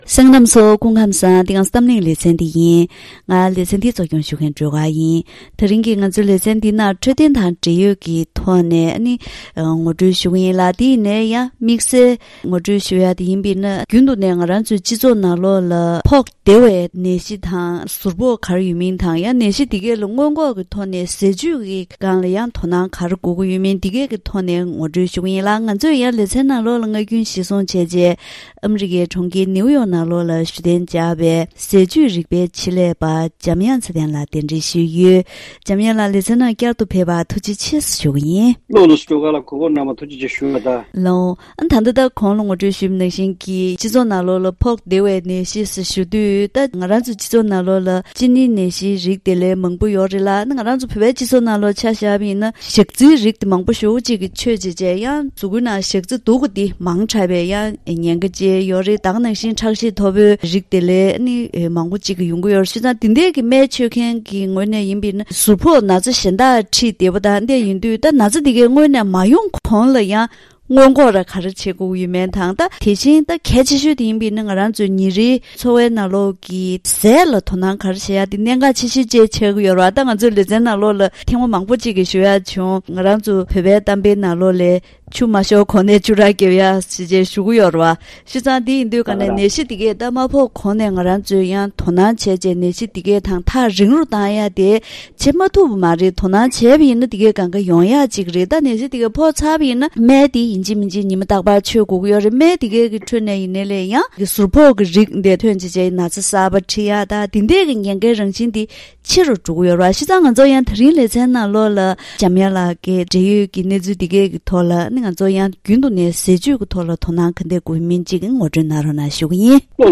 ད་རིང་གི་གཏམ་གླེང་ཞལ་པར་ལེ་ཚན་ནང་སྨན་རིགས་ཁག་བཞིའི་ཟུར་ཕོག་དང་འབྲེལ་བའི་ཐོག་ནས་ཟས་བཅུད་ཀྱི་དོ་སྣང་བྱེད་ཕྱོགས་སྐོར་ཞུ་རྒྱུ་ནས་ཁྲག་ཤེད་དང་གཅིན་སྙིའི་ནད། ཞག་ཚིལ་མཐོ་བའི་ཉེན་འཚབ། ཟུག་བཅག་སྨན་སོགས་བེད་སྤྱོད་བྱེད་པའི་སྐབས་ཉིན་རེའི་ཟས་བཅུད་ལ་དོ་སྣང་ཇི་འདྲ་དགོས་པ་སོགས་འབྲེལ་ཡོད་སྐོར་ལ་ཟས་བཅུད་རིག་པའི་ཆེད་ལས་པ་དང་ལྷན་དུ་བཀའ་མོལ་ཞུས་པ་ཞིག་གསན་རོགས་གནང་།